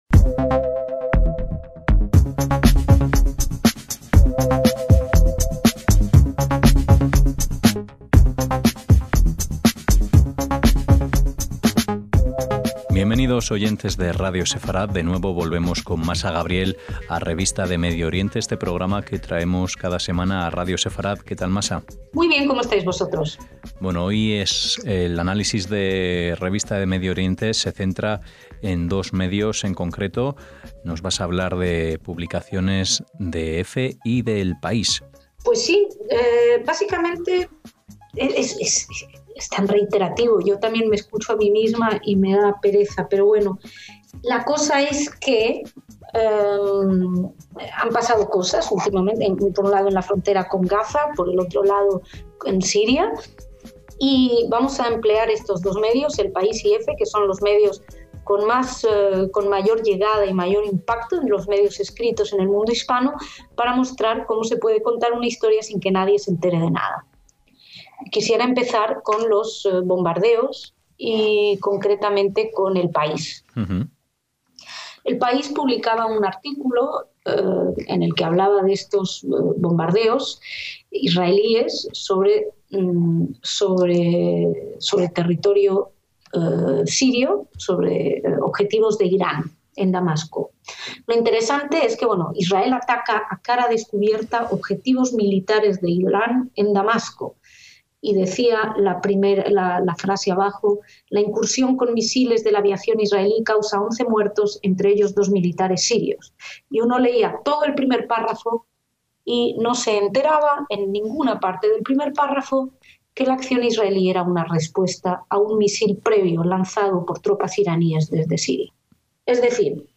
REVISTA DE MEDIO ORIENTE – Análisis semanal de los medios de comunicación